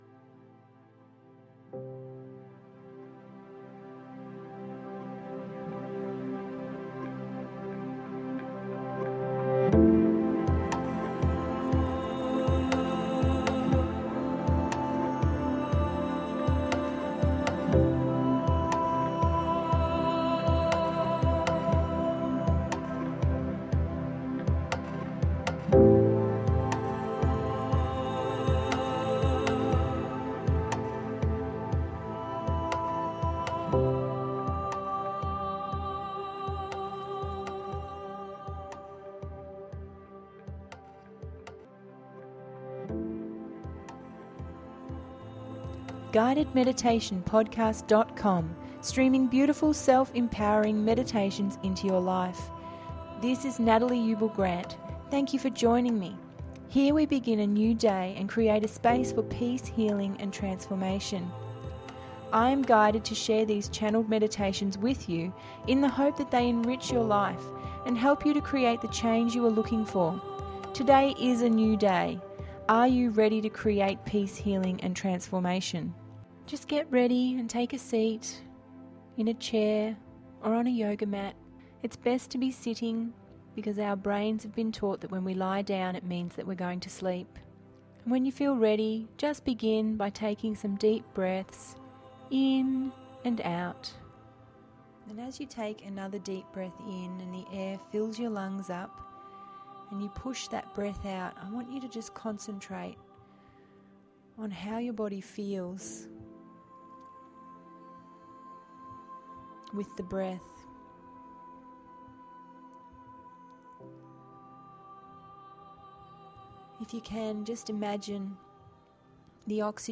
Chakra Sunrise…ep 19 – GUIDED MEDITATION PODCAST